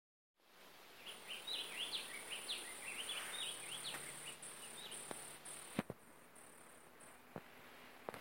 Picaflor Bronceado (Hylocharis chrysura)
Nombre en inglés: Gilded Sapphire
Fase de la vida: Adulto
Localidad o área protegida: Delta del Paraná
Condición: Silvestre
Certeza: Vocalización Grabada
Picaflor_bronceado.mp3